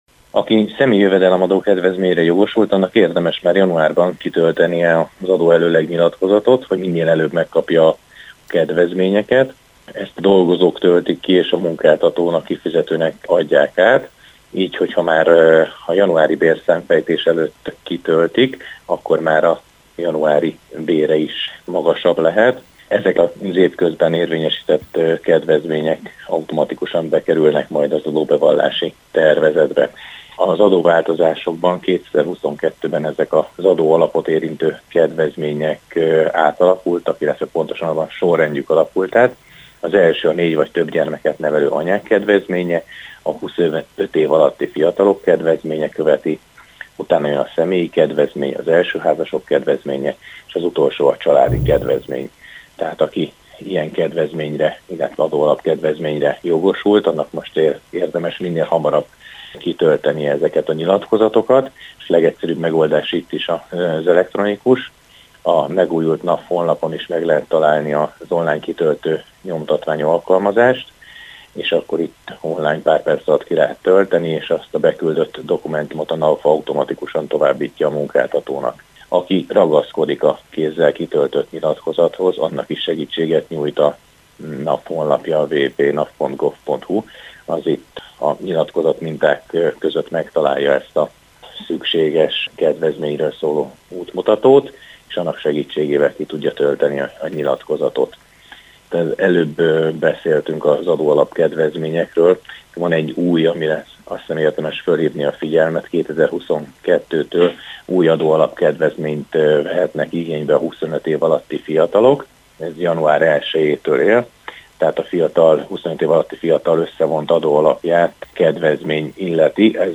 beszélgetett az ÉrdFM 101.3 Zónázójában